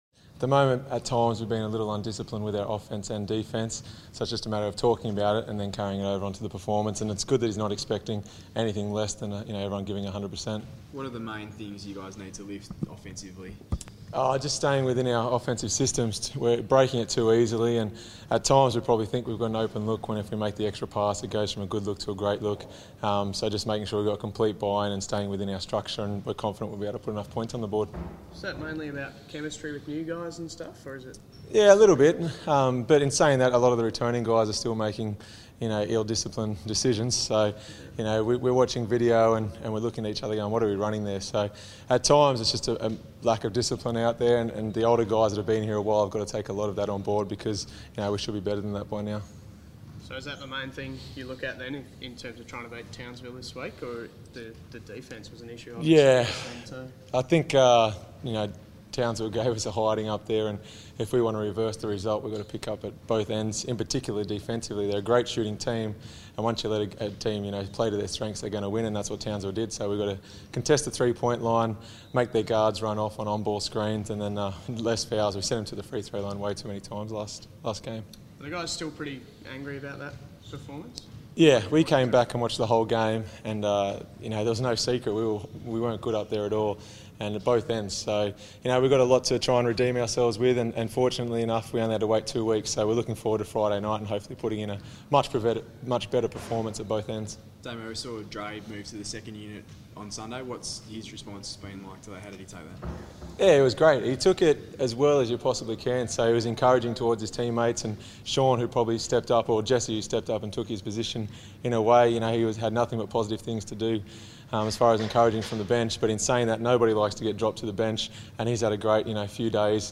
press conference